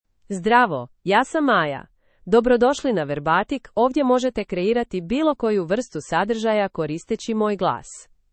Maya — Female Croatian (Croatia) AI Voice | TTS, Voice Cloning & Video | Verbatik AI
Maya is a female AI voice for Croatian (Croatia).
Voice sample
Listen to Maya's female Croatian voice.
Maya delivers clear pronunciation with authentic Croatia Croatian intonation, making your content sound professionally produced.